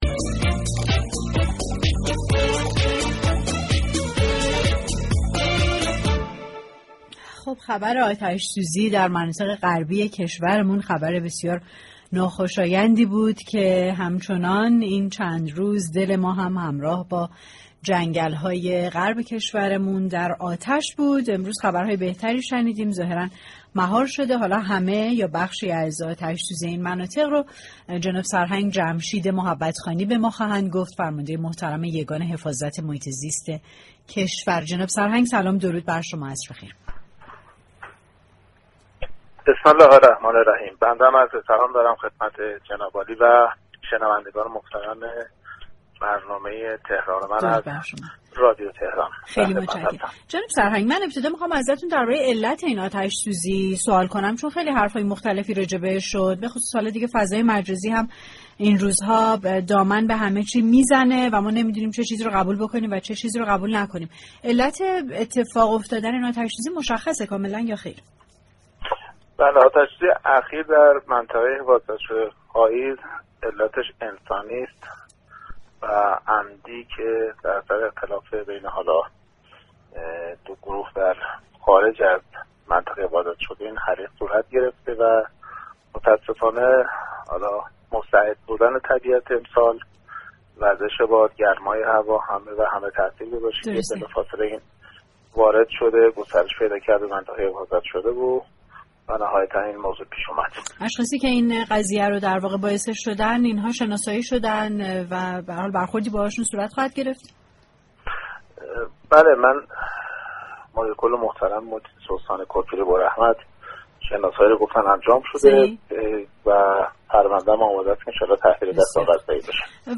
فرماندهی یگان حفاظت محیط زیست كشور در گفتگویی با تهران من، علت اتفاق آتش‌سوزی در منطقه‌ی خاییز را برای مخاطبان رادیو تهران بازگو كرد.